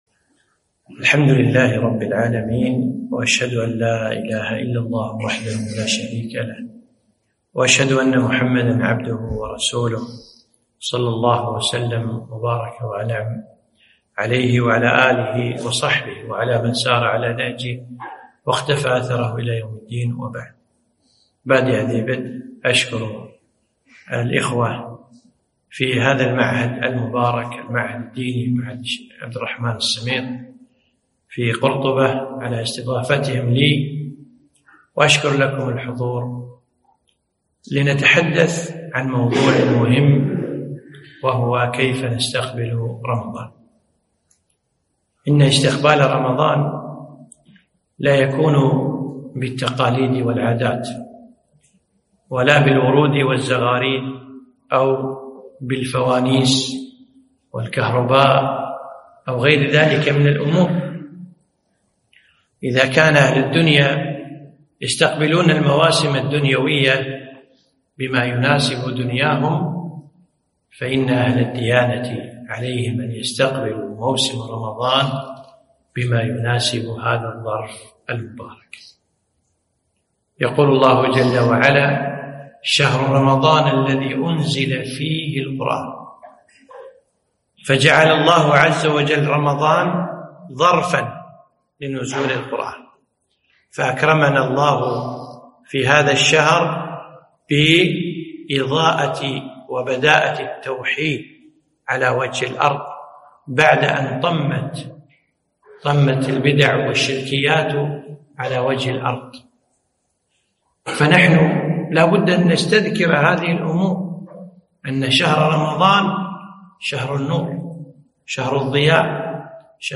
محاضرة - كيف نستقبل رمضان؟